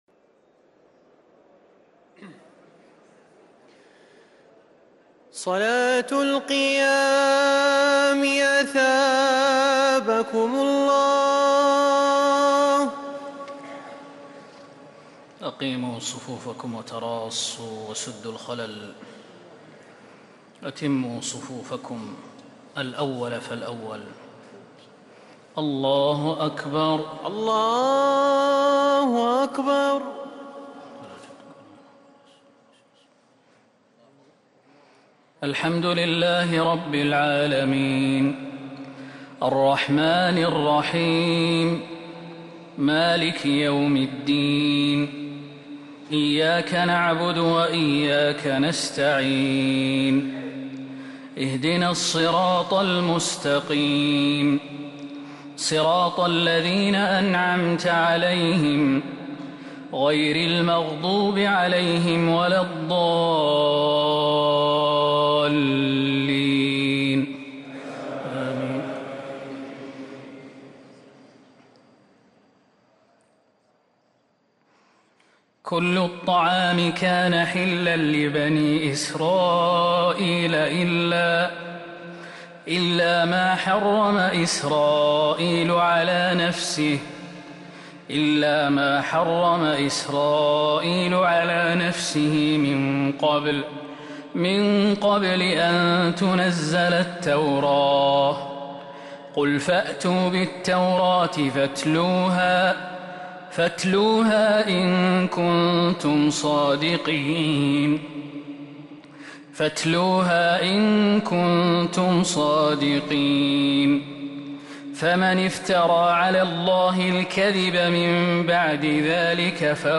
تراويح ليلة 5 رمضان 1444هـ من سورة آل عمران (93-158) | Taraweeh 5st night Ramadan 1444H Surah Aal-i-Imraan > تراويح الحرم النبوي عام 1444 🕌 > التراويح - تلاوات الحرمين